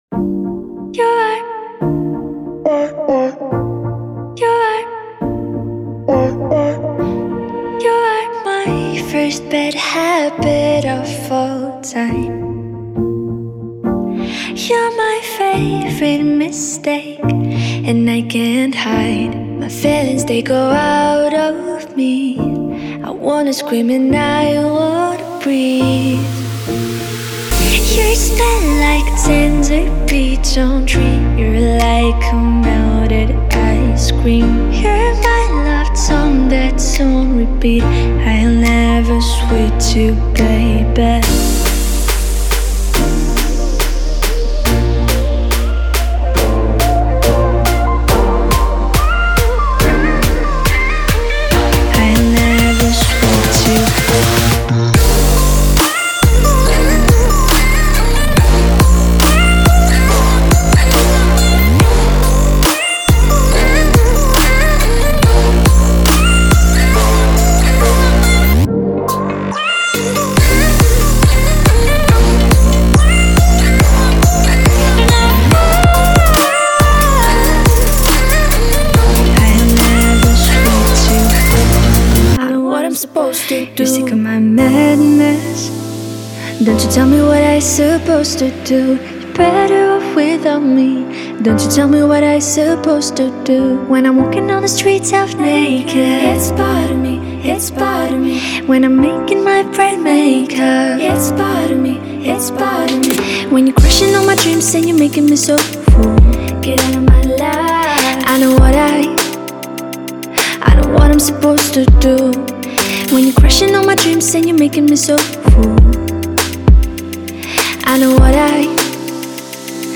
在这个庞大的文件包中，您会发现快速的踢，拍手和军鼓，带有所有相关MIDI文件的鼓舞人心的音
乐循环，节奏性的鼓声填充和循环，动听的声音效果和自定义预设的血清预设。
歌手的独特无伴奏合唱曲目!您可以在演示轨道中听到所有这些构建套件的预览。